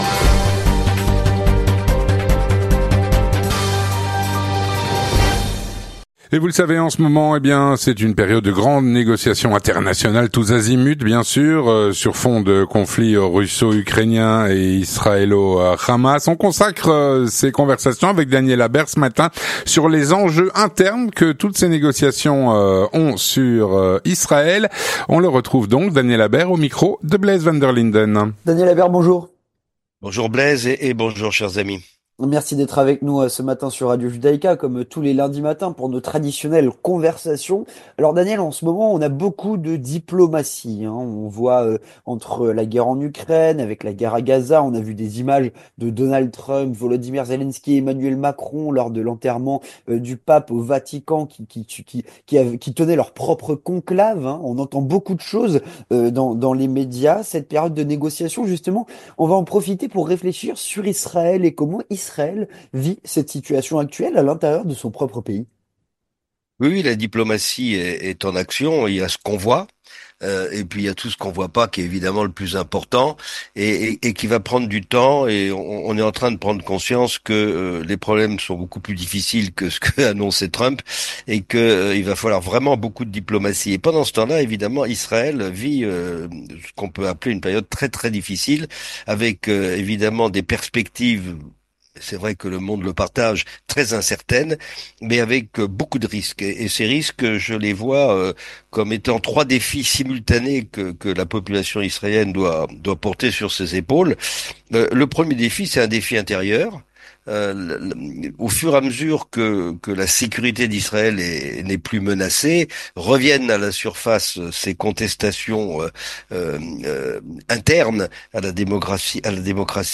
Il est au micro